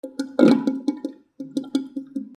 / M｜他分類 / L30 ｜水音-その他
洗面所 排水口がゴポゴポ鳴る